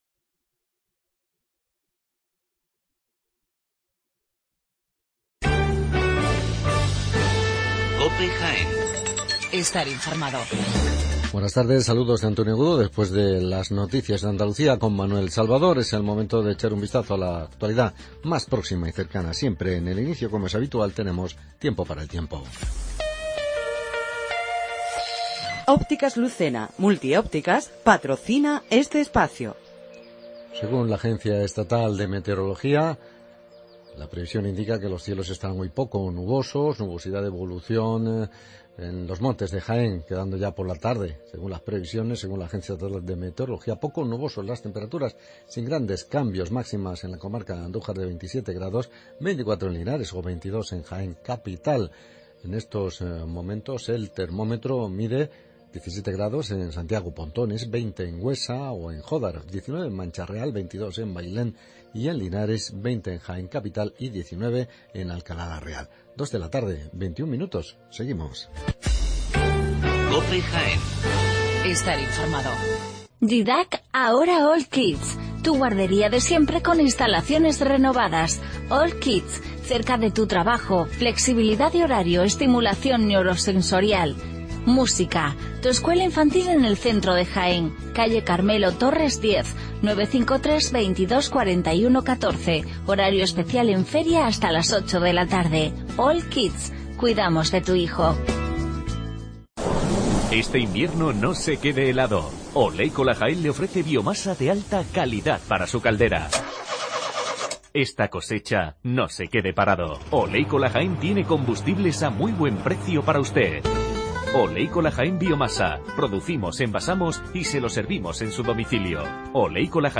Informativo con toda la actualidad de Jaén